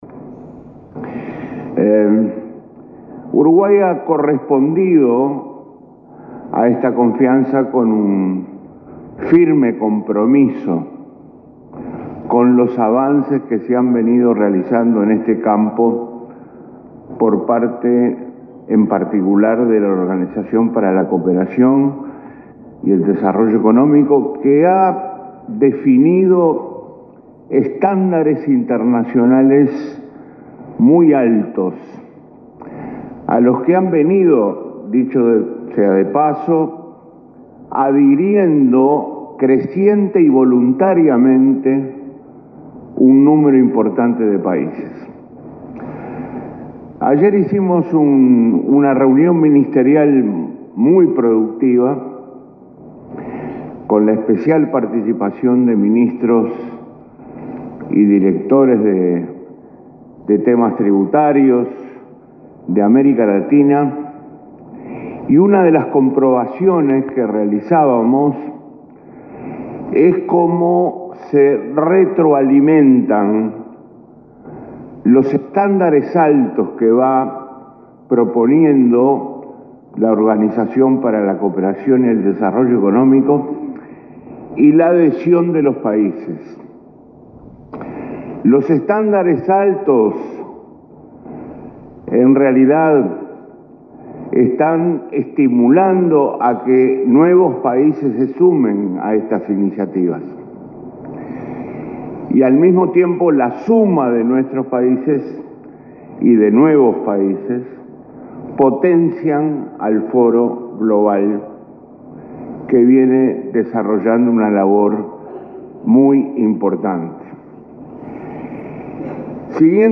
Los principios como la libertad, la democracia, el multilateralismo, el desarme y el uso ilegal de la fuerza forman parte de la inserción internacional de calidad que impulsa Uruguay, así como el incremento del comercio y la producción nacional, destacó el ministro Astori en la XI Reunión del Foro Global sobre Transparencia e Intercambio de Información con Fines Fiscales que se realiza entre este 20 y 22 en Punta del Este.